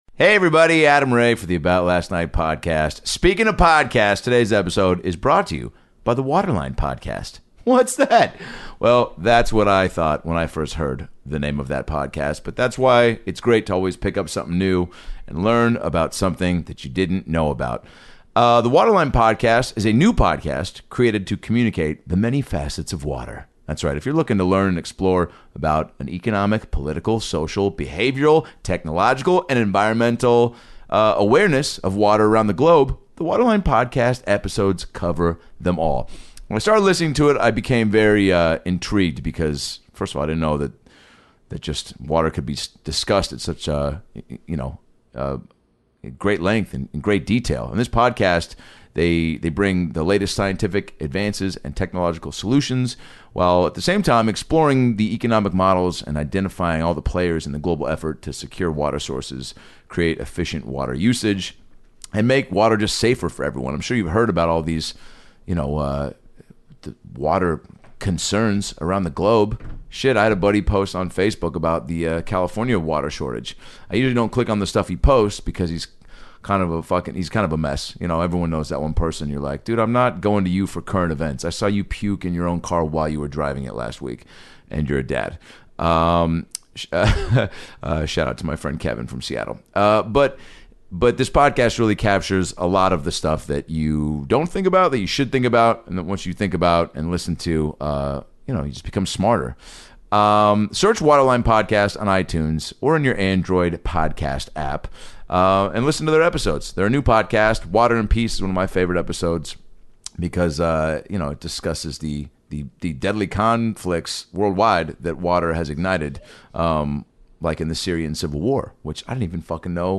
Adam is reunited with his buddy Josh Wolf, and shortly into the episode, are laughing hysterically when Adam's weird hiccup problem won't stop! They also discuss flying (and farting on airplanes), Josh's grandma, high school sports, body image issues, how mean kids can be when it comes to nicknames, share stories about animal experiences they've had, and a TON of other hilarious topics!